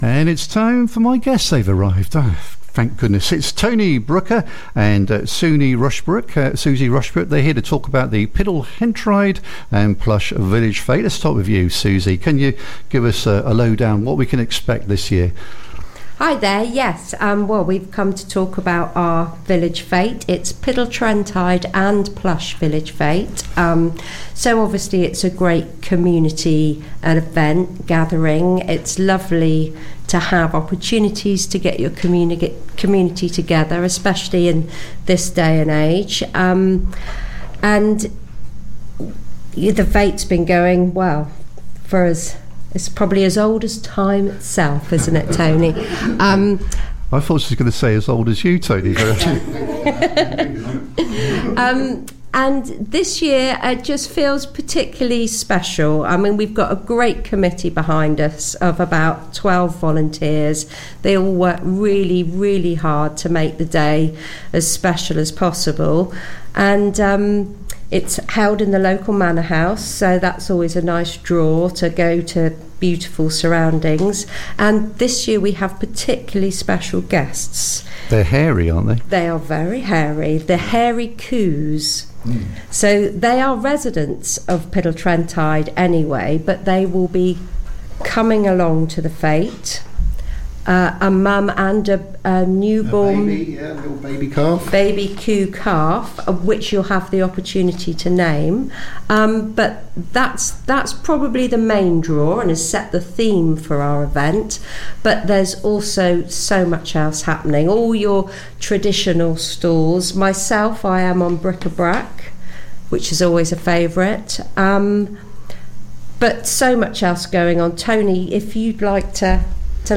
We welcomed to the studio